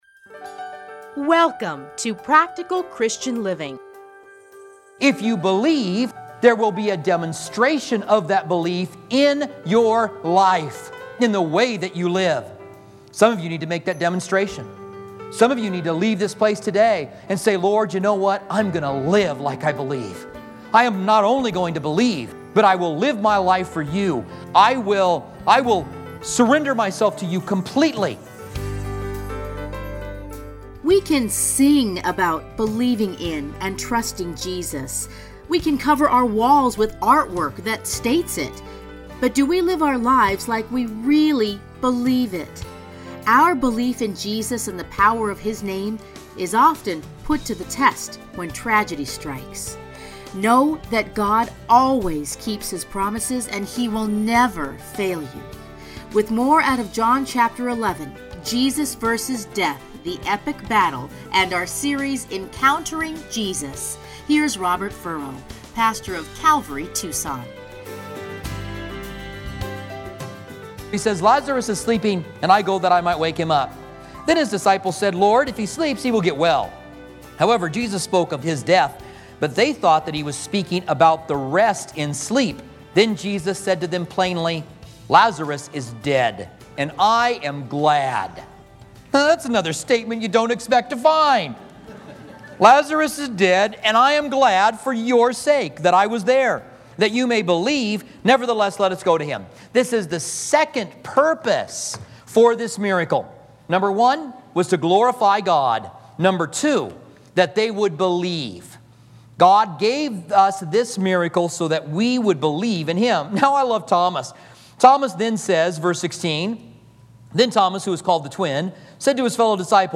30-minute radio programs